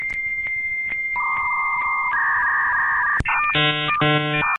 Звуки старого модема
Здесь вы можете слушать и скачивать знаменитые сигналы dial-up соединения: от начального гудка до узнаваемых помех.
Короткий звук зависания перед вопросом